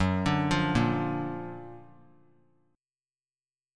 Listen to F#+M7 strummed